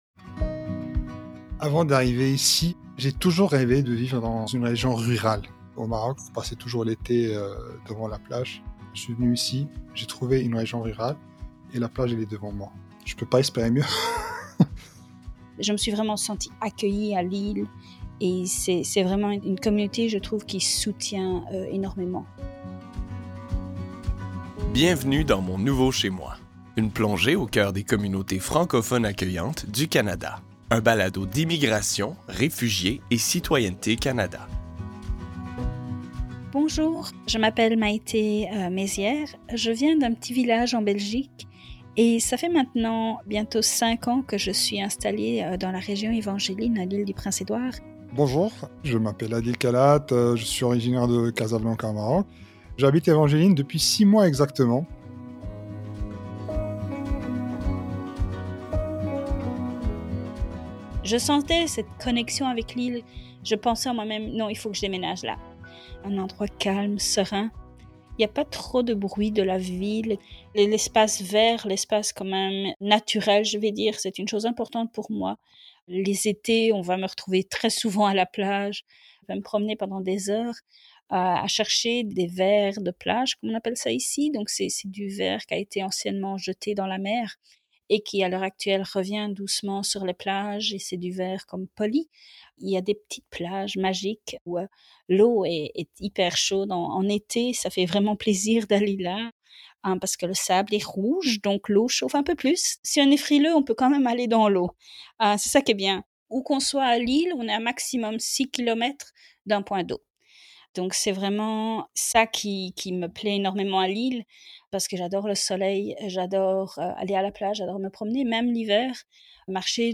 Écoutez deux immigrants parler de leur expérience et leur arrivée dans leur nouvelle communauté.